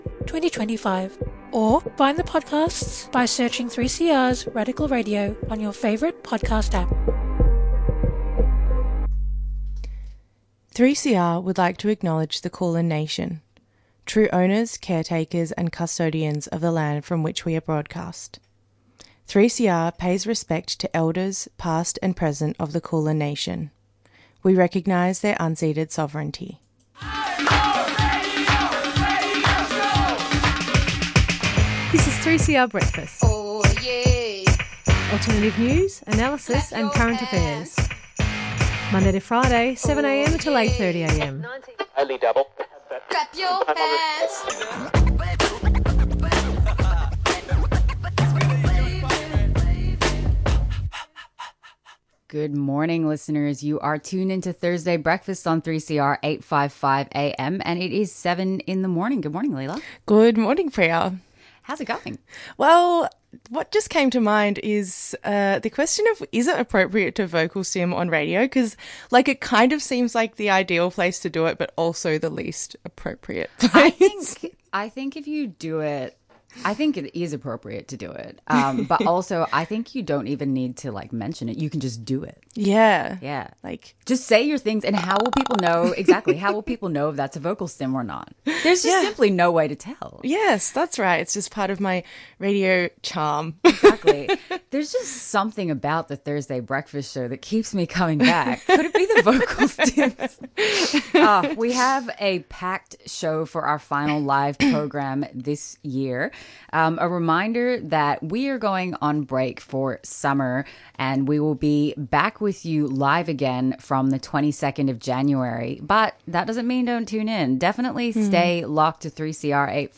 Acknowledgement of Country// Headlines//